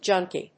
/dʒˈʌŋki(米国英語), ˈdʒʌŋki:(英国英語)/